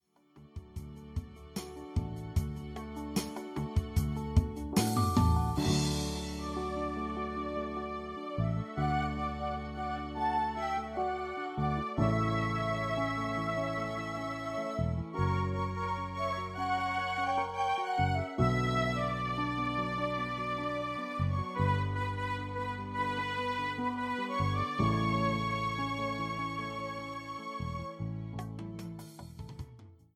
91-Grupero-Balada-2.mp3